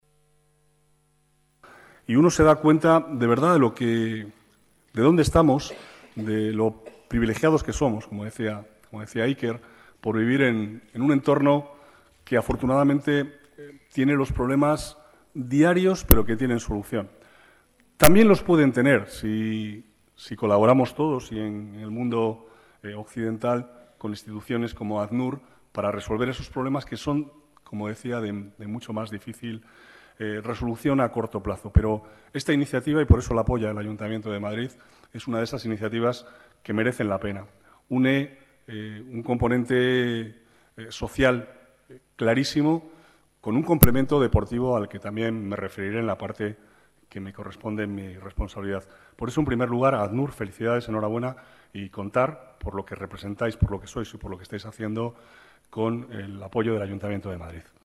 Nueva ventana:Palabras del vicealcalde, Manuel Cobo